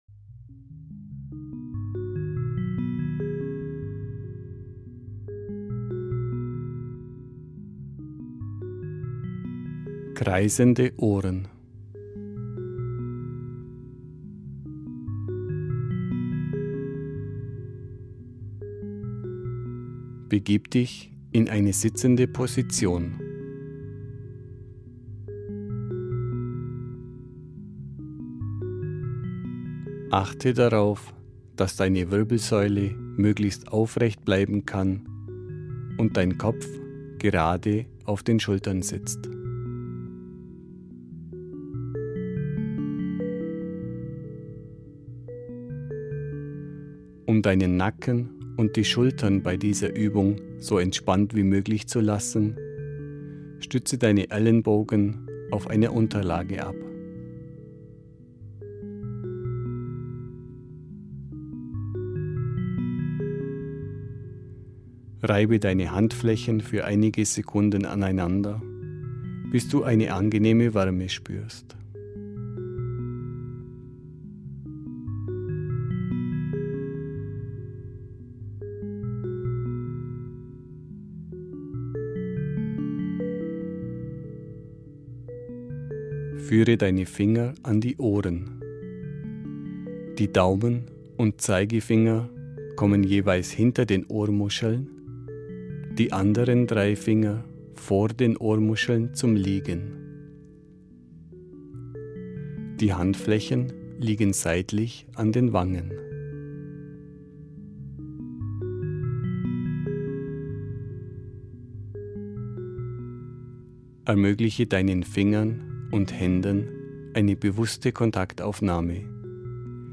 Die speziell dafür komponierte Musik ist mit klangvollen Heilfrequenzen unterlegt.